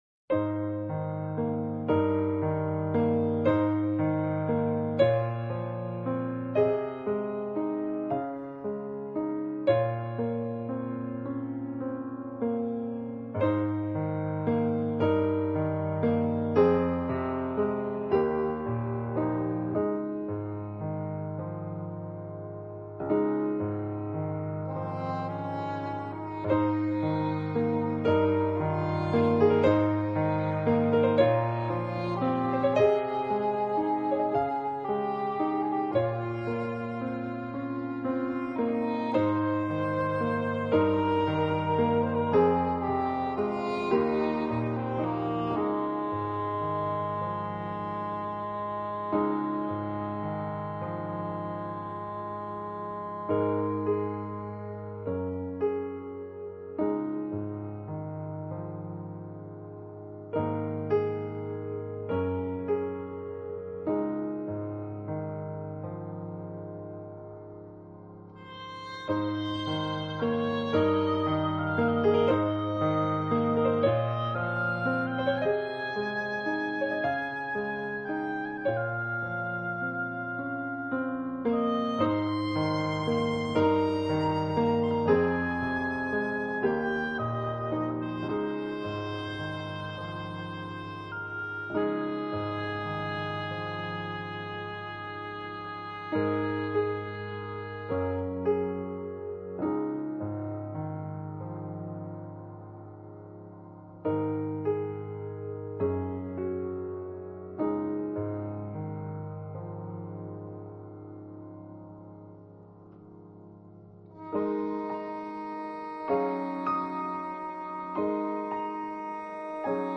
Style: Neo-classic